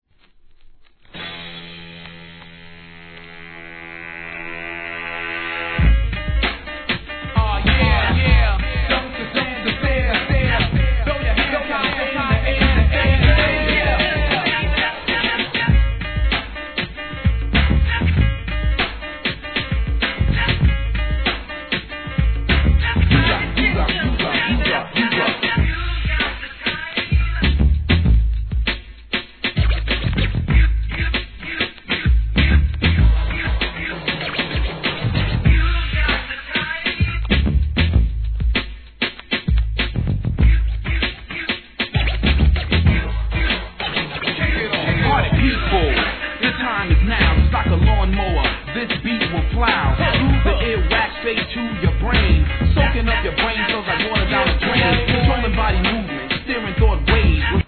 HIP HOP/R&B
濃厚エレクトロ!